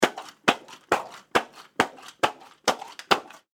水ヨーヨー2.mp3